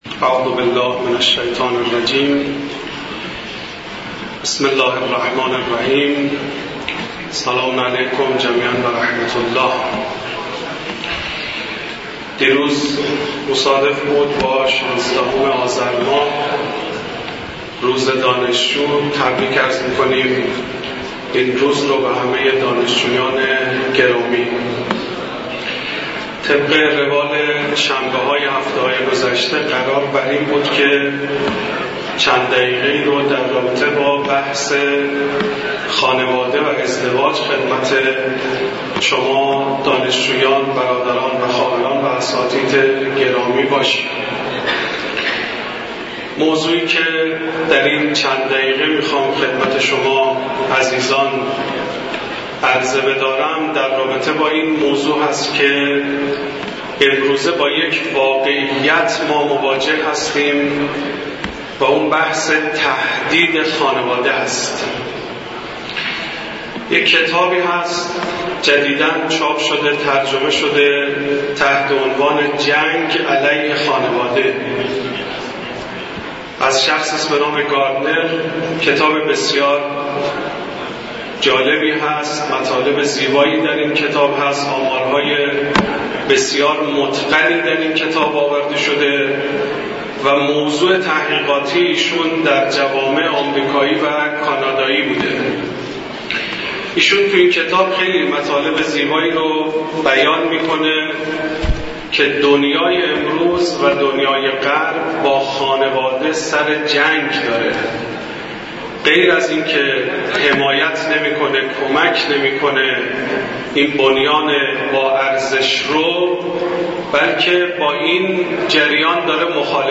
بیان مباحث مربوط به خانواده و ازدواج در کلام مدرس حوزه و دانشگاه در مسجد دانشگاه کاشان